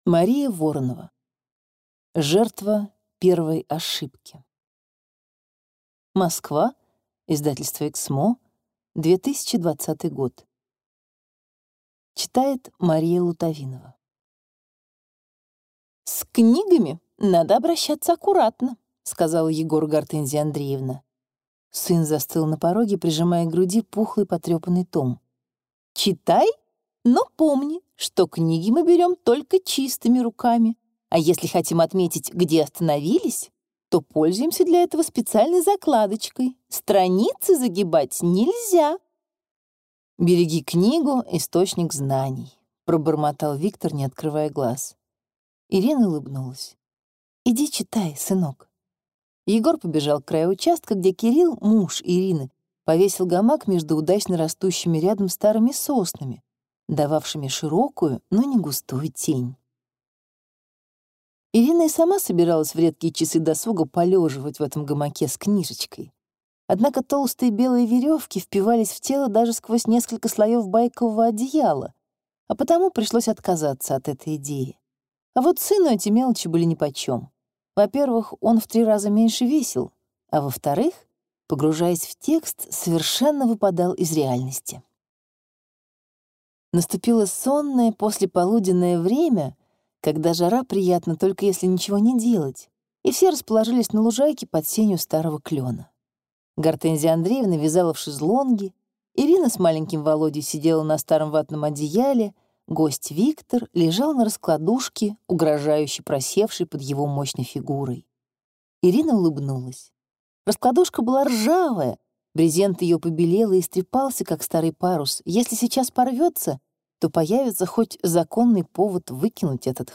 Аудиокнига Жертва первой ошибки | Библиотека аудиокниг
Прослушать и бесплатно скачать фрагмент аудиокниги